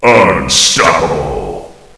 flak_m/sounds/announcer/int/unstoppable.ogg at df55aa4cc7d3ba01508fffcb9cda66b0a6399f86